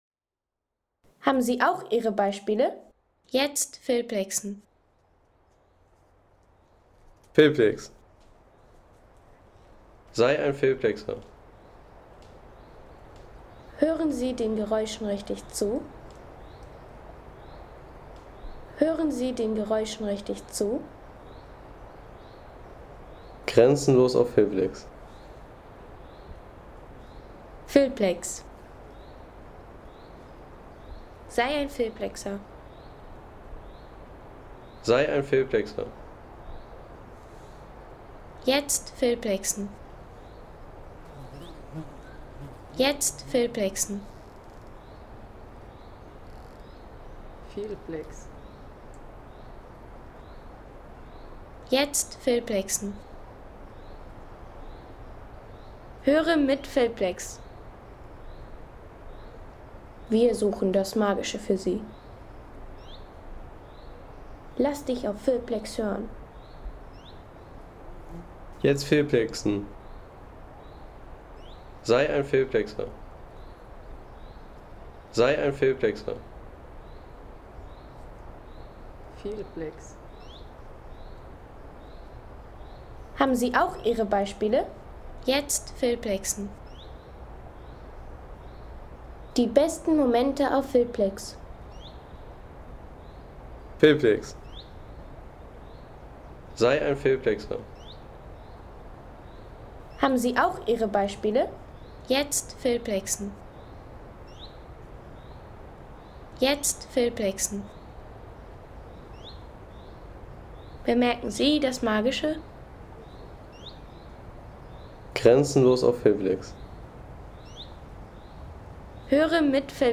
Burg Eltz / Elzbachtal Home Sounds Landschaft Wälder Burg Eltz / Elzbachtal Seien Sie der Erste, der dieses Produkt bewertet Artikelnummer: 243 Kategorien: Wälder - Landschaft Burg Eltz / Elzbachtal Lade Sound.... Hören Sie das Tal der Burg Eltz – Ein Märchen inmitten der Natur.